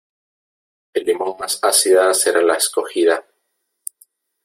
Pronunciato come (IPA) /seˈɾa/